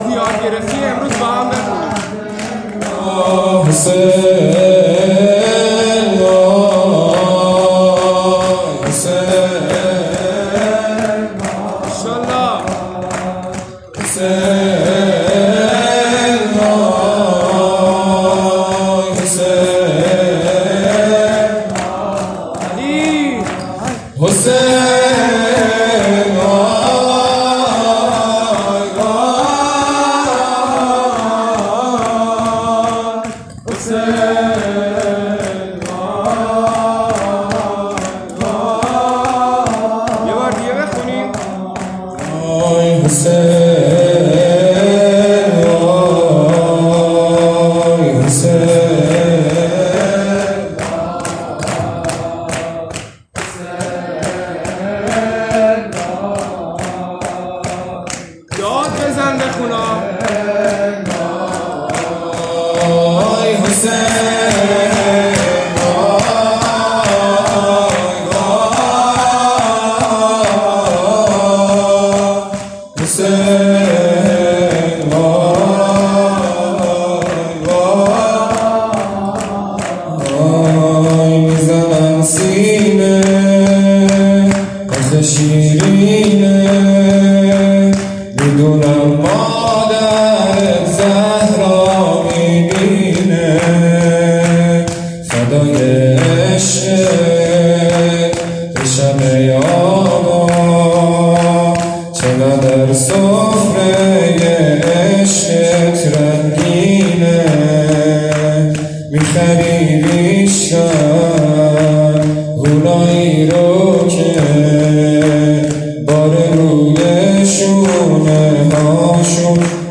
مداحی
شب اول محرم 99